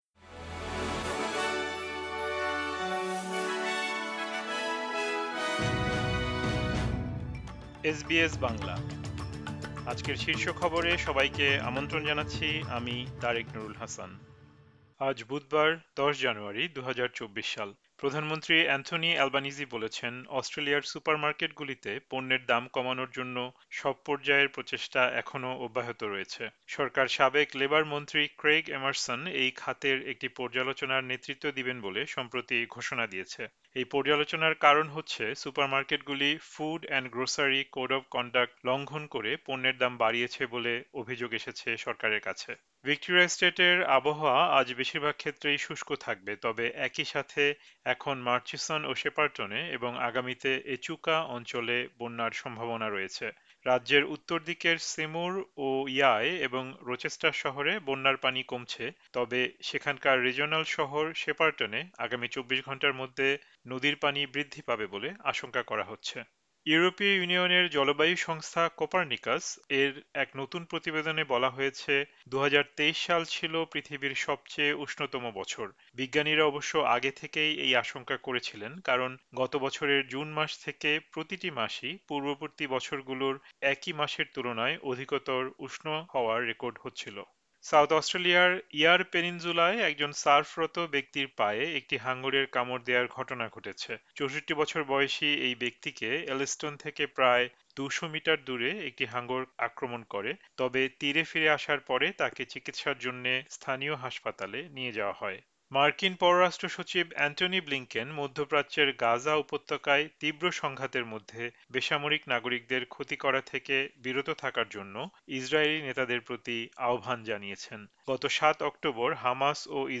এসবিএস বাংলা শীর্ষ খবর: ১০ জানুয়ারি, ২০২৪